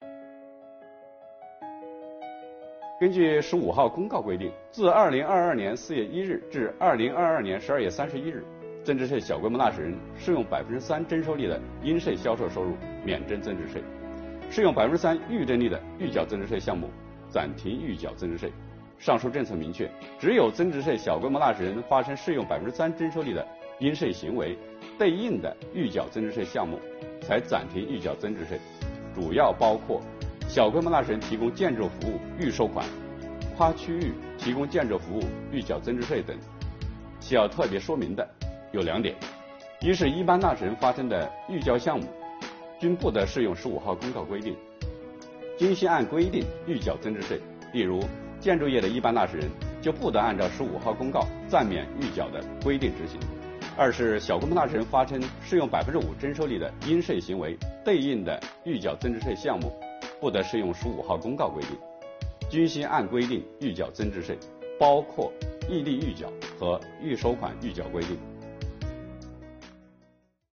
本期课程由国家税务总局货物和劳务税司副司长刘运毛担任主讲人，对小规模纳税人免征增值税政策进行详细讲解，方便广大纳税人进一步了解掌握相关政策和管理服务措施。